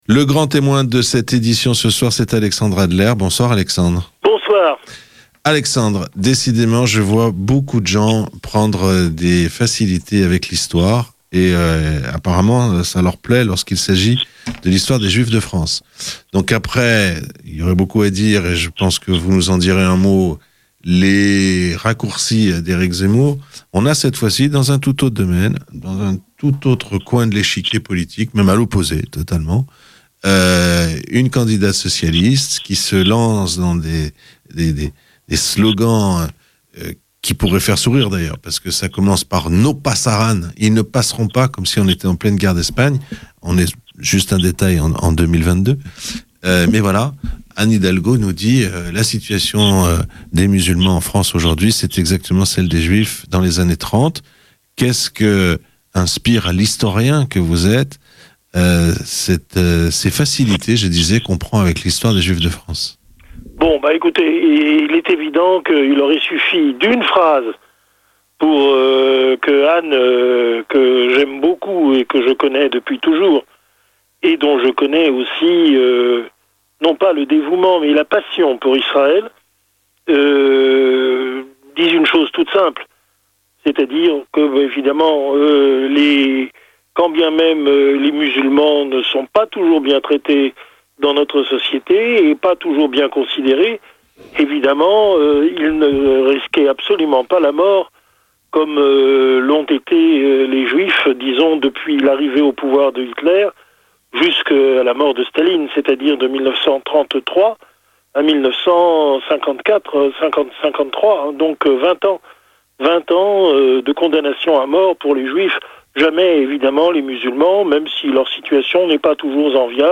Invité du journal de 18h00, l'historien Alexandre Adler a évoqué Eric zemmour mais aussi Anne Hidalgo (issue d'une famille de Marannes, des Juifs convertis de force sous l'Inquisition, selon lui et Alin Finkielkraut